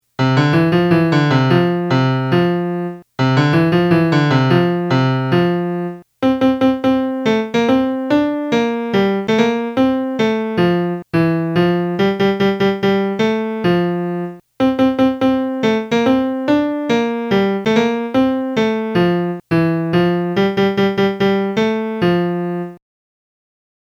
e-mi-la-donna-bionda-melody.mp3